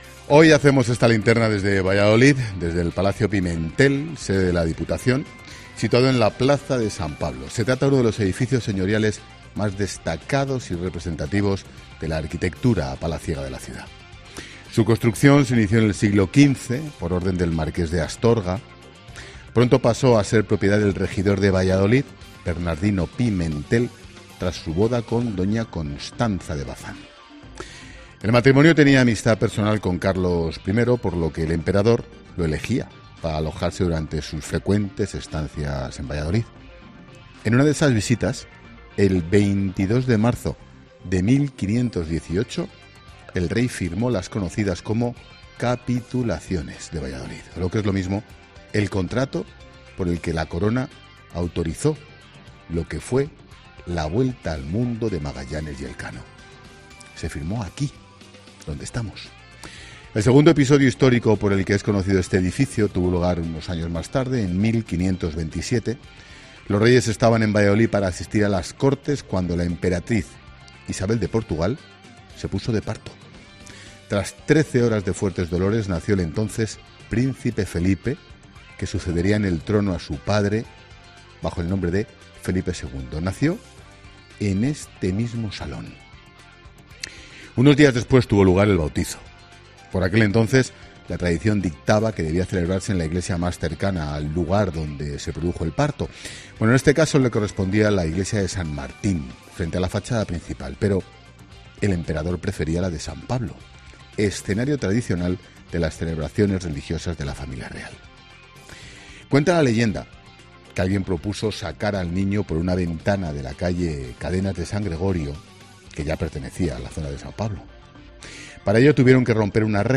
Monólogo de Expósito
El director de 'La Linterna' analiza desde Valladolid el preacuerdo al que han llegado ERC y JxCat en Cataluña